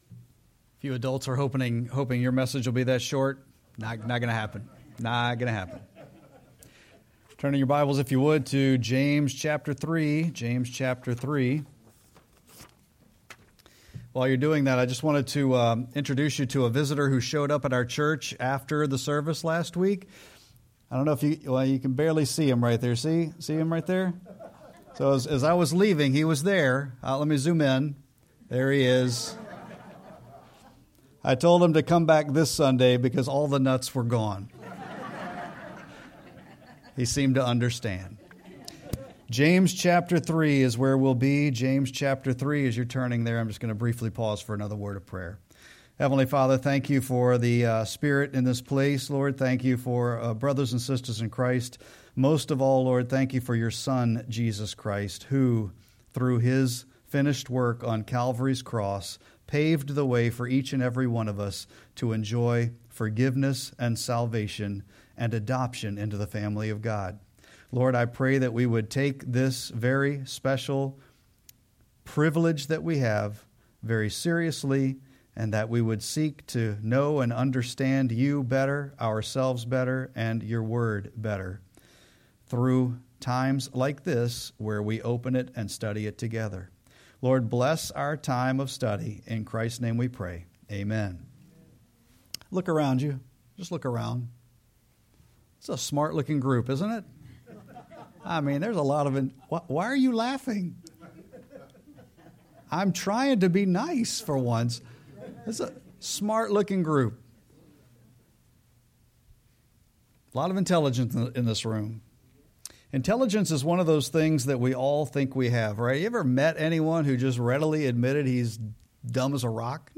Sermon-8-10-25.mp3